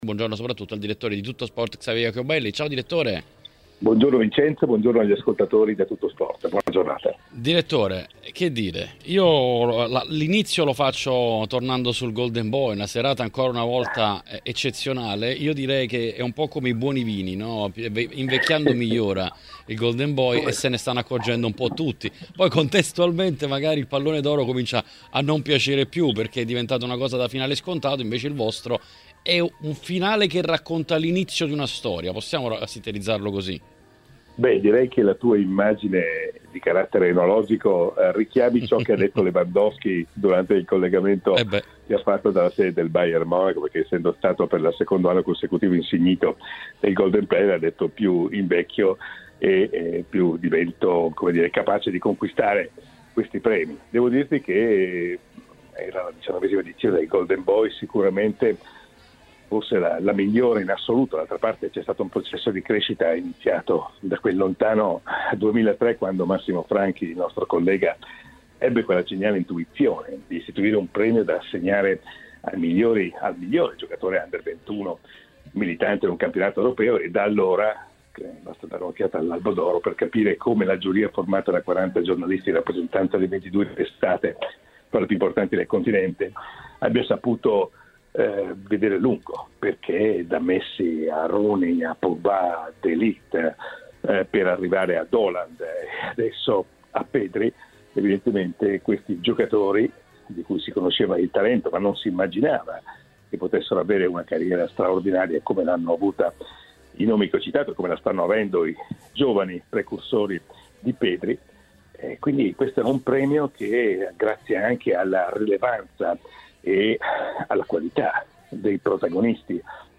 ospite nell’editoriale di TMW Radio.